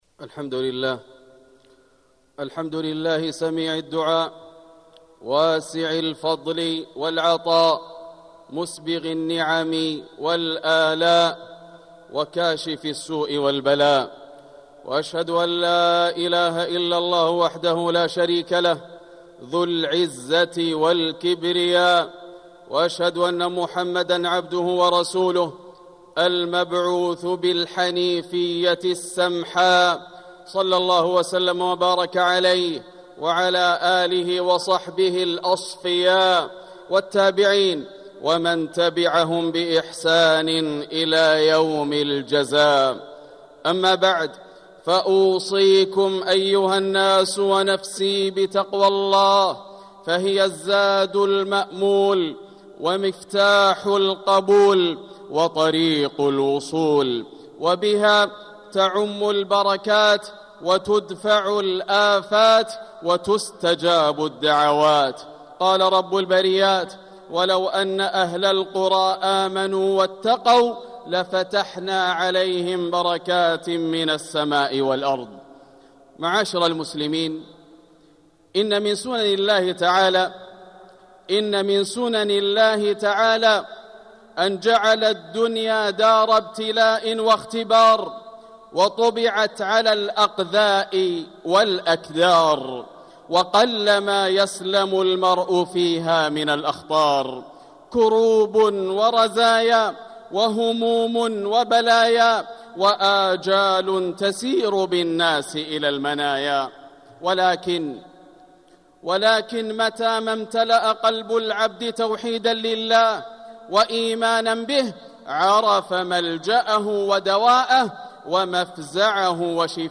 مكة: تعظيم والدعاء - ياسر بن راشد الدوسري (صوت - جودة عالية. التصنيف: خطب الجمعة